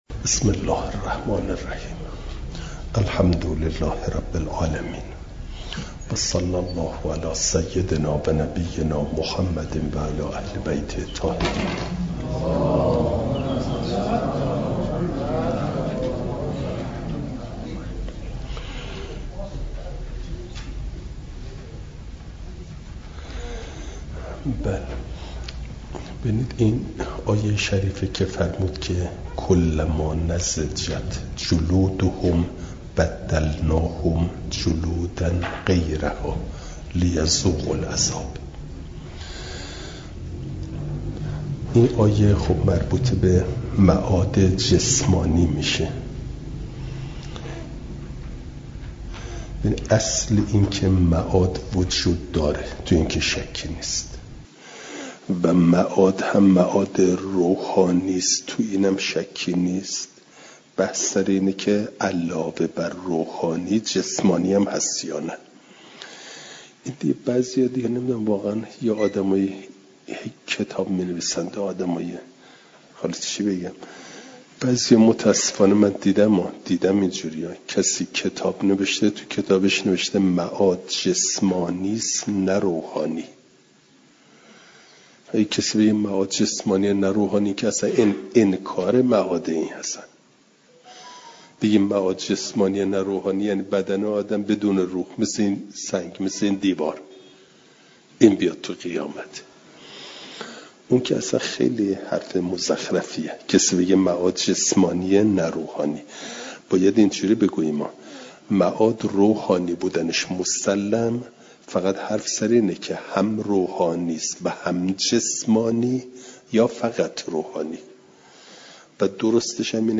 جلسه سیصد و شصت و نهم درس تفسیر مجمع البیان